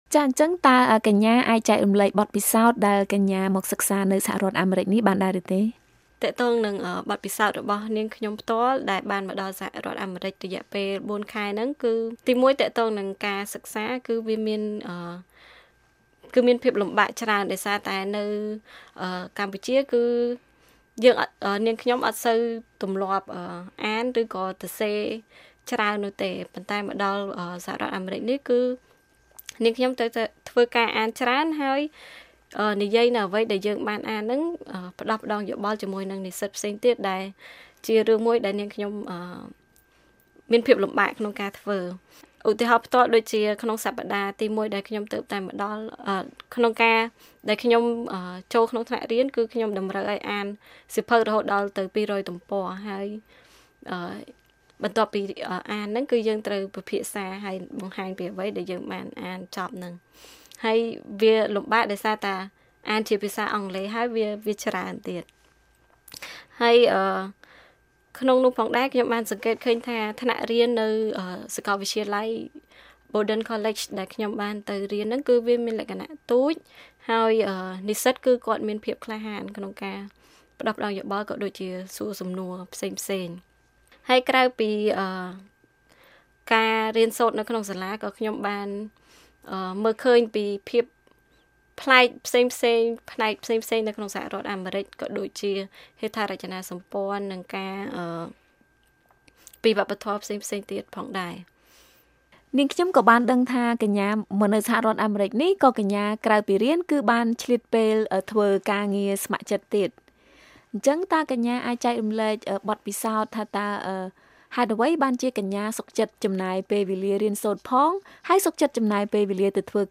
បទសម្ភាសន៍VOA៖ និស្សិត Harpswell ថា ការងារស្ម័គ្រចិត្តផ្តល់អត្ថប្រយោជន៍ដល់ខ្លួនឯងនិងសង្គមទាំងមូល